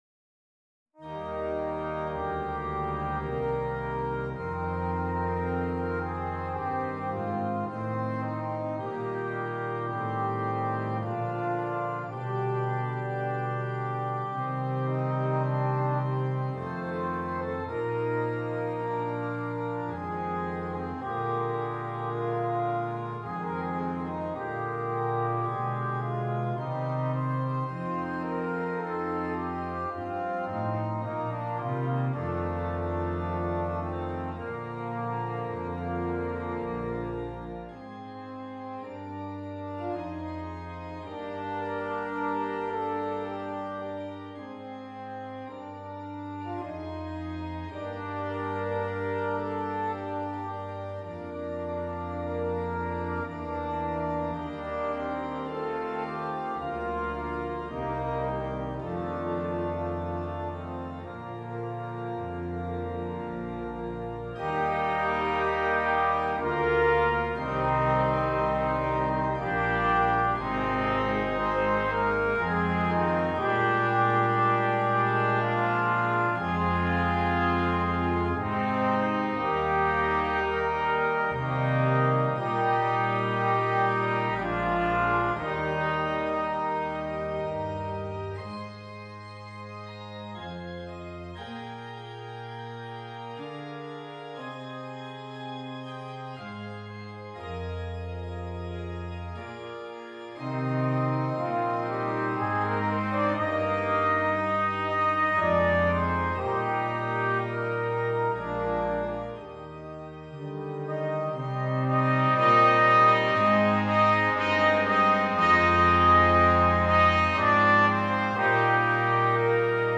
Voicing: Brass Quintet